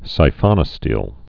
(sī-fŏnə-stēl, sīfə-nə-stēlē)